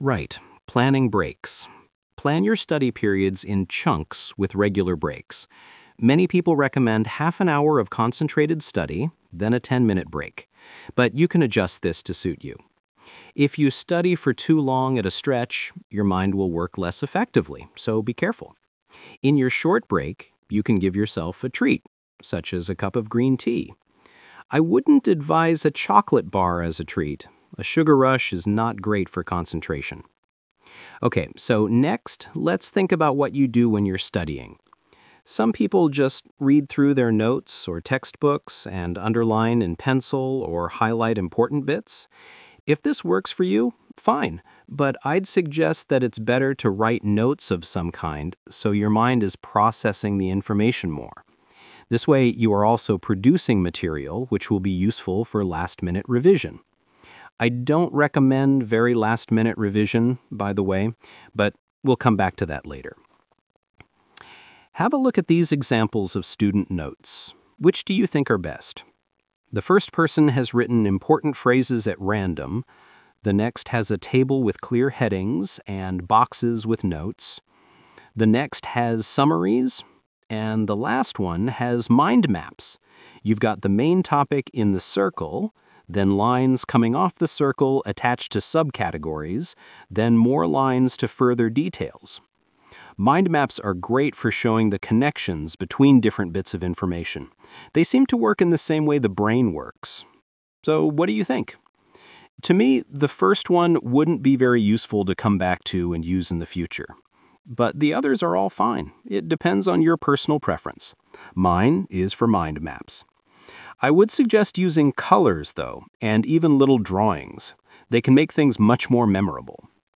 Talk/Lecture 2: You will hear a talk about how to prepare for exams.